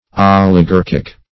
Oligarchic \Ol`i*gar"chic\, Oligarchical \Ol`i*gar"chic*al\, a.